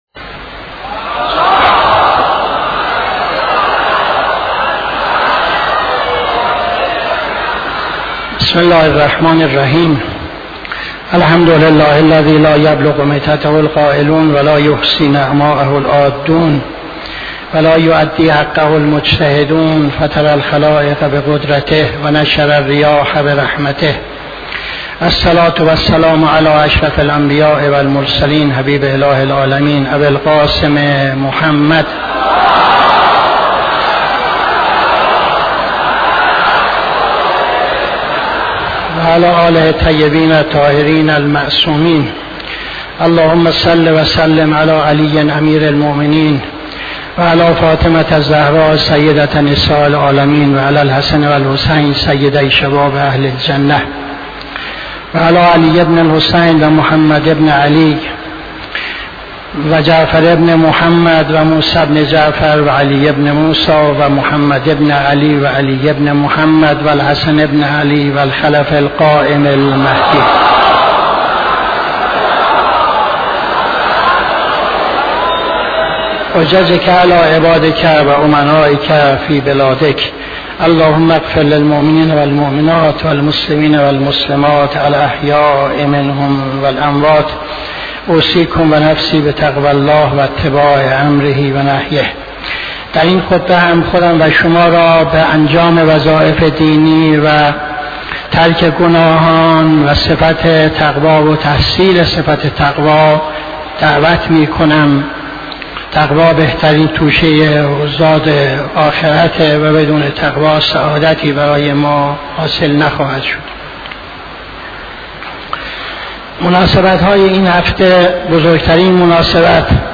خطبه دوم نماز جمعه 09-05-77